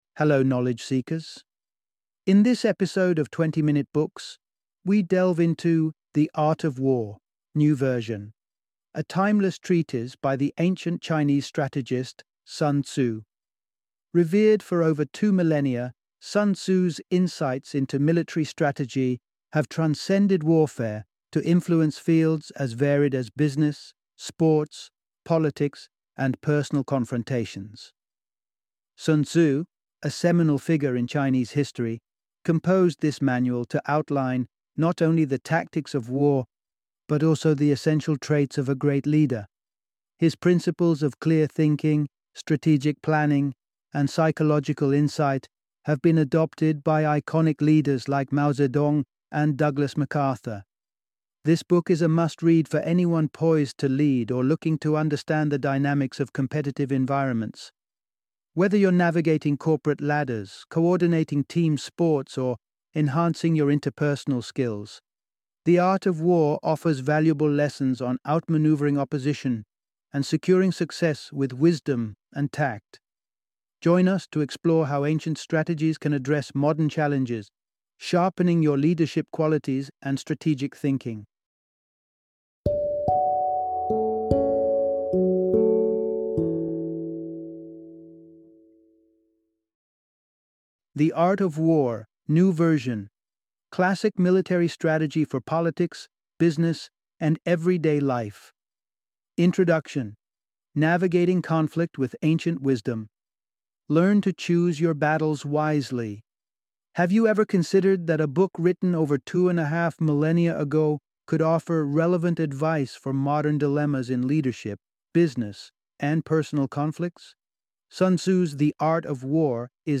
The Art of War (New Version) - Audiobook Summary